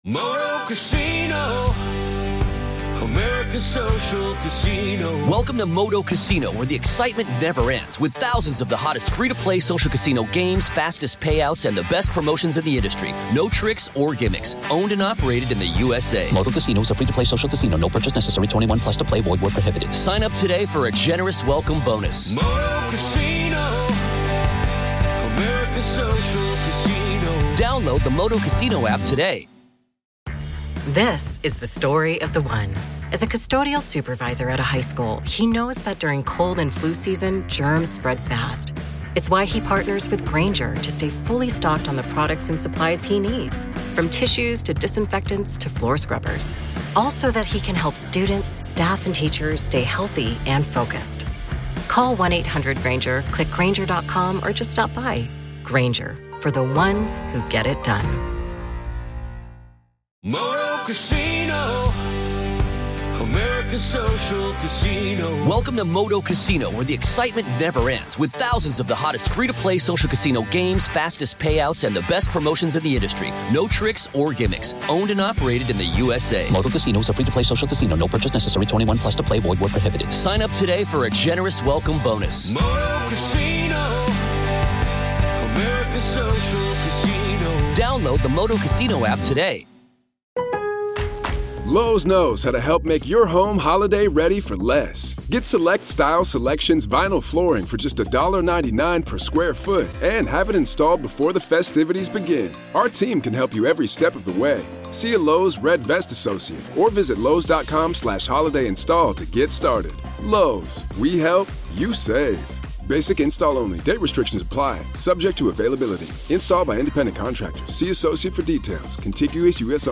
VERDICT WATCH-COURT AUDIO-Missing Mom Murder Trial